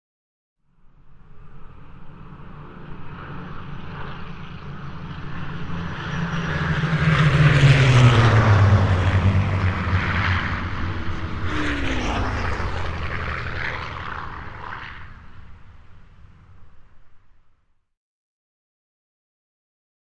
Cargo Series Of 2: Medium; High-speed Approach At Low Altitude. Low Engine Drones With Sharp Buzzing Edge, Fast, Screaming Flybys And Short Away. Medium To Distant Perspective. Prop Plane.